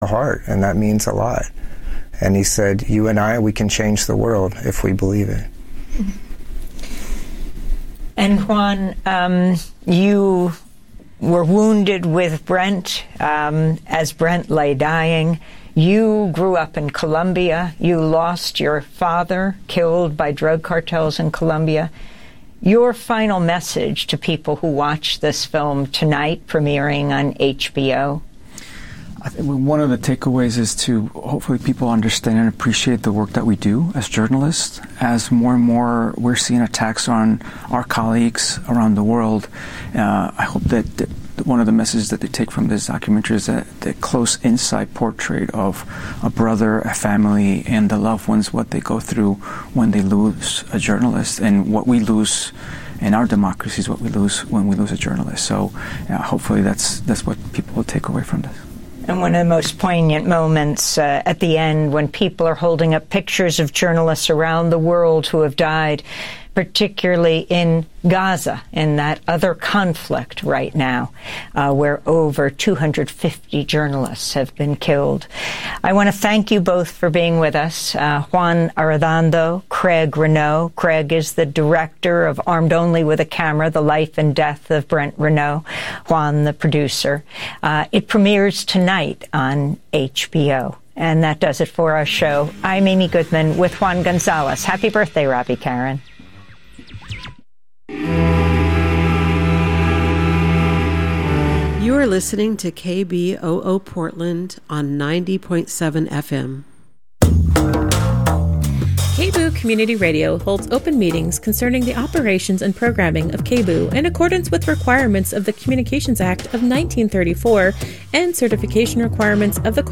Hosted by: KBOO News Team
Non-corporate, community-powered, local, national and international news